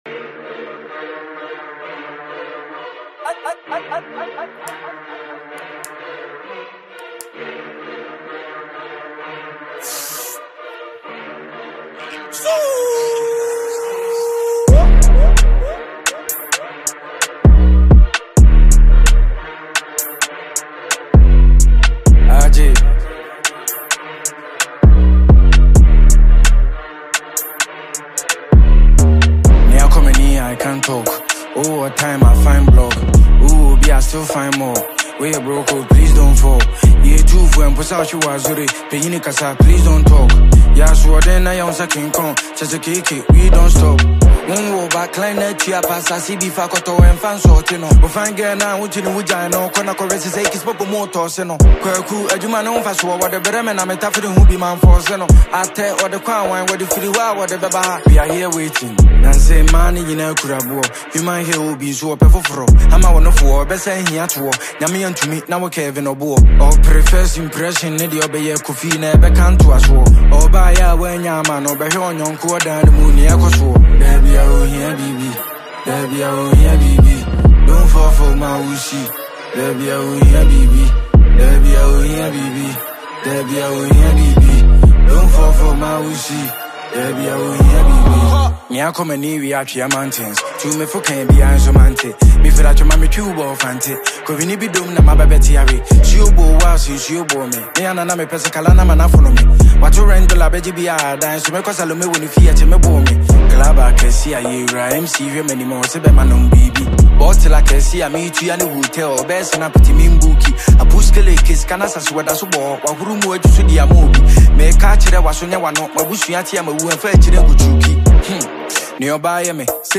Ghana Music Music
drill and hip-hop energy